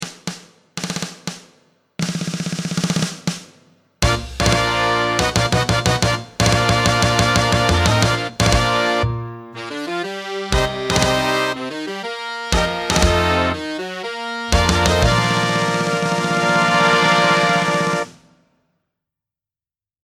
big band instrumentation
Big Band Instrumental